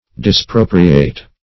Search Result for " dispropriate" : The Collaborative International Dictionary of English v.0.48: Dispropriate \Dis*pro"pri*ate\, v. t. [L. dis- + propriare to appropriate, fr. proprius one's own, proper.] To cancel the appropriation of; to disappropriate.
dispropriate.mp3